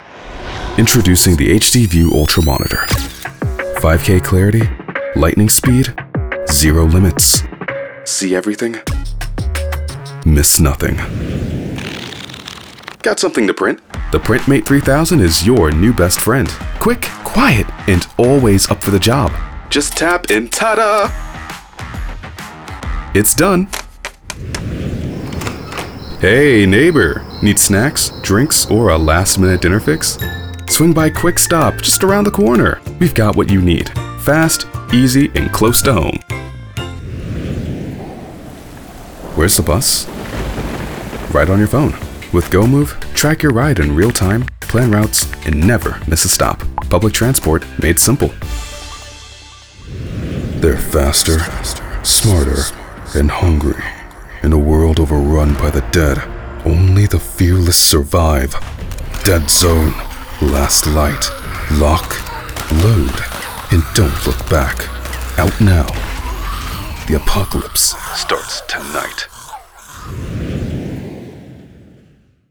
Smooth Voice with a fast turnaround time
Commercial Demo
Standard American, New York
Young Adult